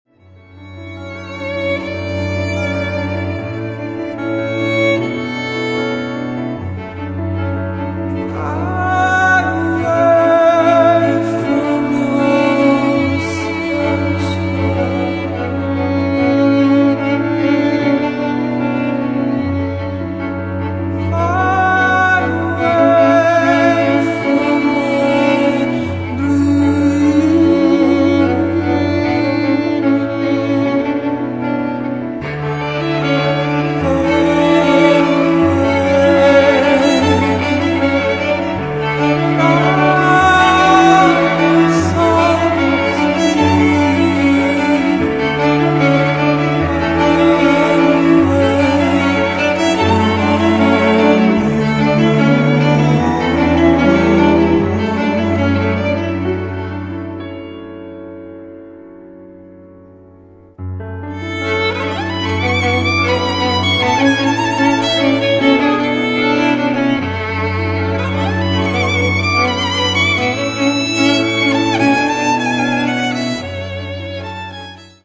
voice,guitar
piano
violin